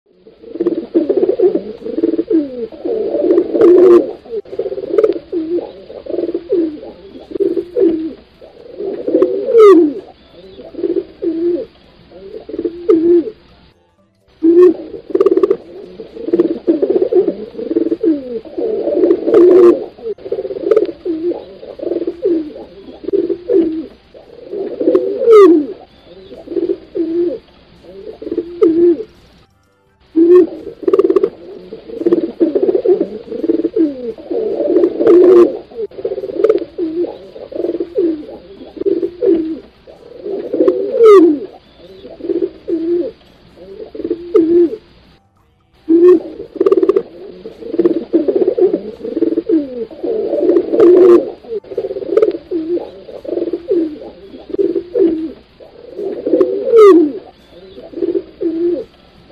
Tiếng Bồ Câu Gáy MP3